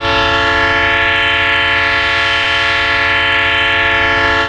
Each sound Fx file has been digitally remastered from their original sources for optimum sound level and reproduction on the PFx Brick.